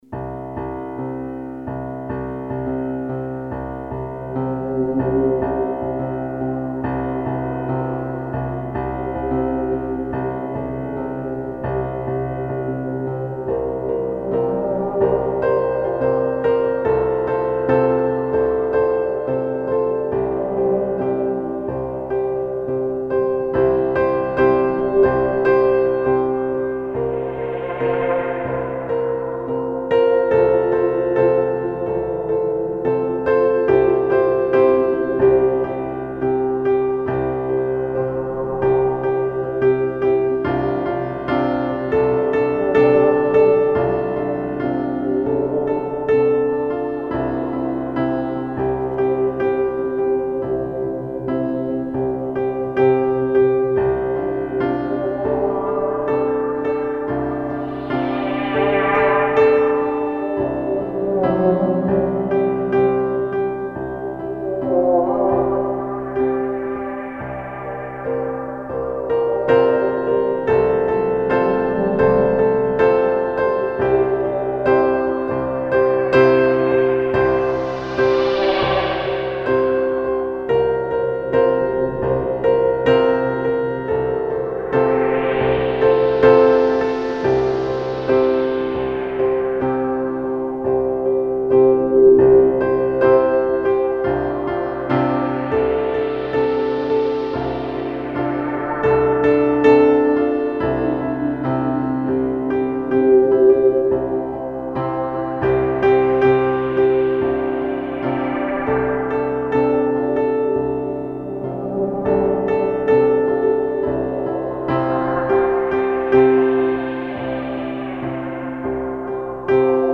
Ruhiges Piano mit sphärischen Synthiesounds.
Tempo: 75 bpm / Datum: 29.07.2013